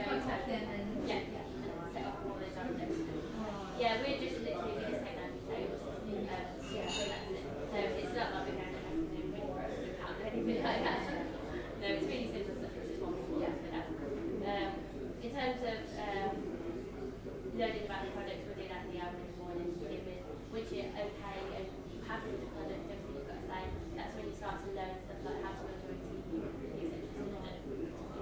Pyramid sales interview
90288-pyramid-sales-interview.mp3